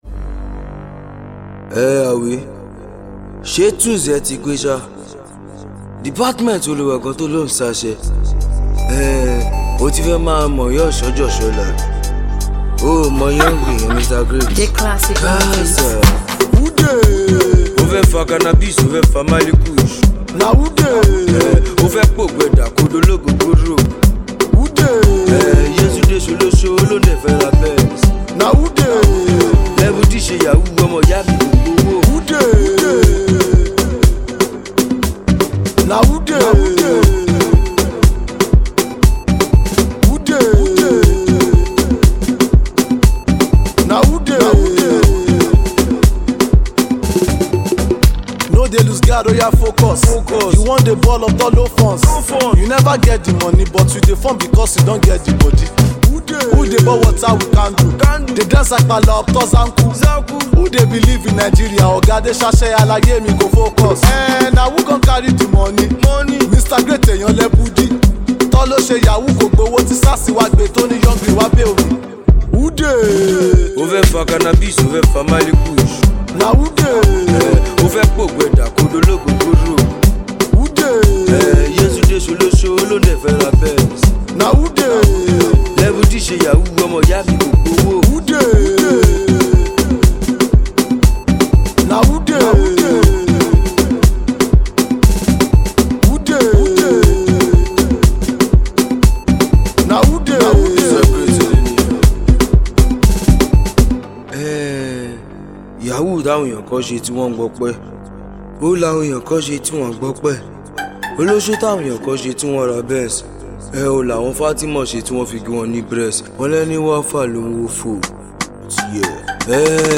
Talented rapper
This club banger